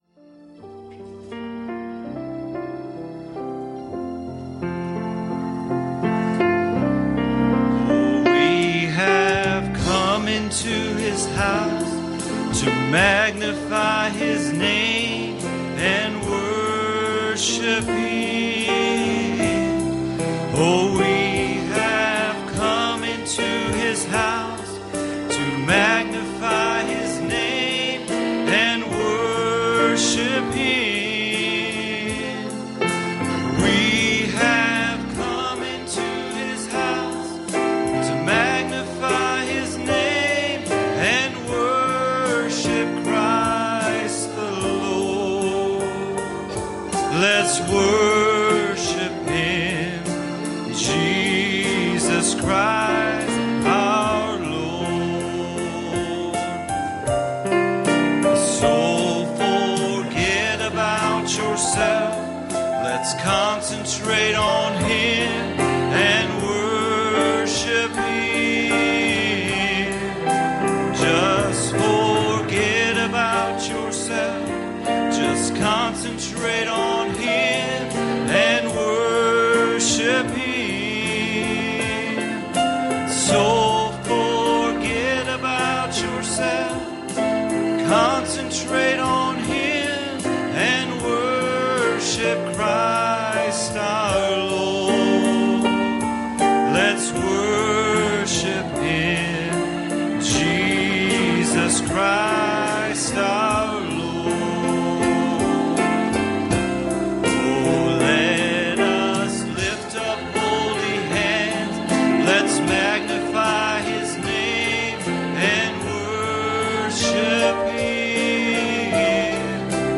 Matthew 5:4 Service Type: Sunday Evening "And now